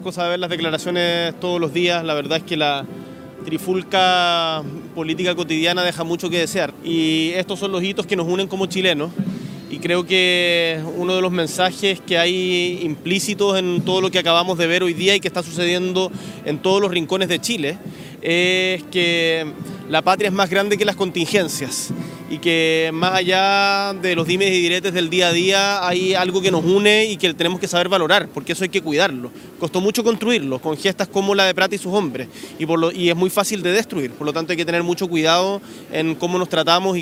El Presidente de la República, Gabriel Boric Font, encabezó este miércoles la conmemoración del Día de las Glorias Navales en la Plaza Sotomayor de Valparaíso, un evento que, aunque más breve que en años anteriores con un contingente de 1.070 efectivos, fue significativo por las reflexiones compartidas por el mandatario.
Si bien no hubo un discurso formal, el Presidente Boric dedicó algunas palabras en la transmisión oficial, enfatizando la importancia de esta fecha.